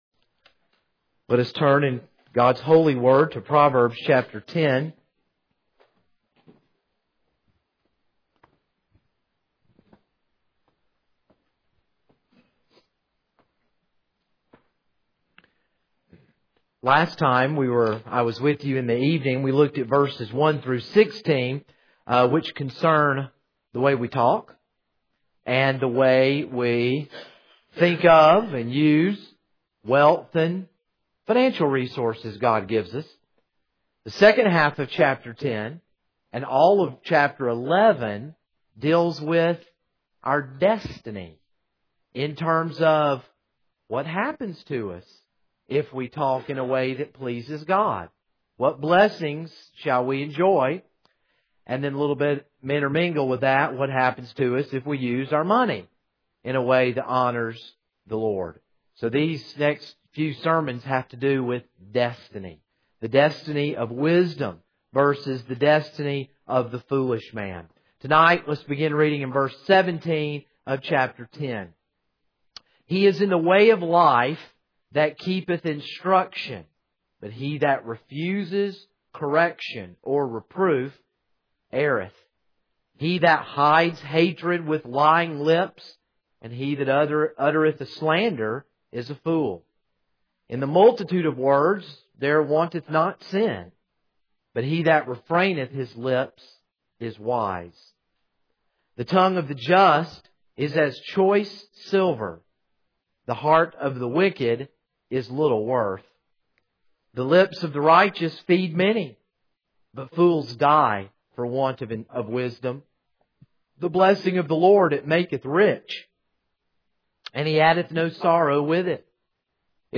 This is a sermon on Proverbs 10:17-32.